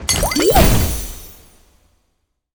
potions_mixing_alchemy_01.wav